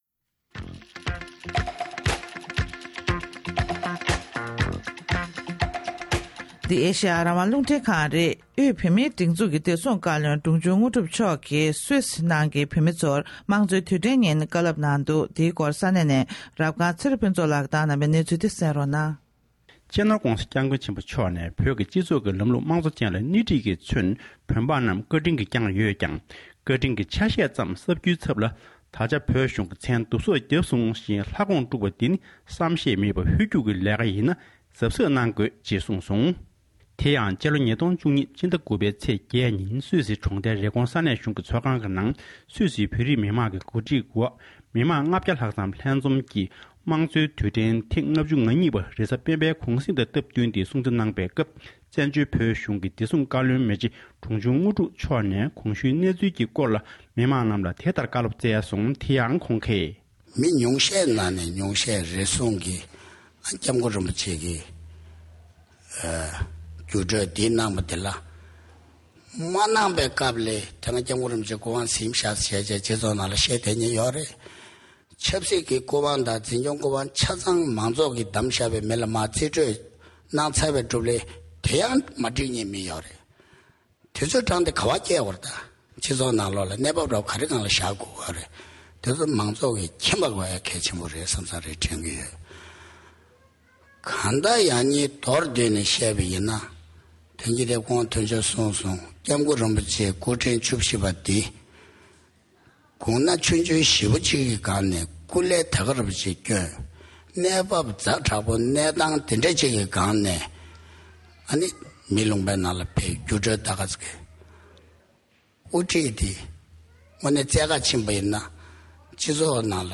དབུས་བོད་མིའི་སྒྲིག་འཛུགས་ཀྱི་བདེ་སྲུང་བཀའ་བློན་དངོས་གྲུབ་མཆོག་གིས་སུས་སི་བོད་མི་ཚོར་གསུང་བཤད།
སྒྲ་ལྡན་གསར་འགྱུར།